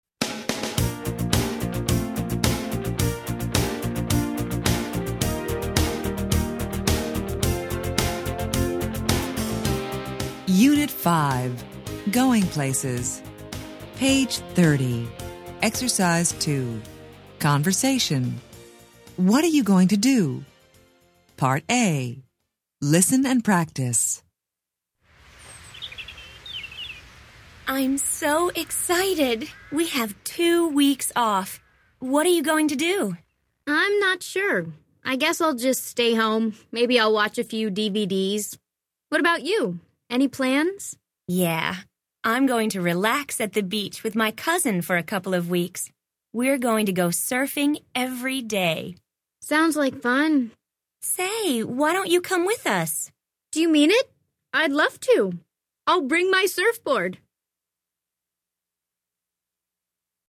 Interchange Third Edition Level 2 Unit 5 Ex 2 Conversation Track 9 Students Book Student Arcade Self Study Audio
interchange3-level2-unit5-ex2-conversation-track9-students-book-student-arcade-self-study-audio.mp3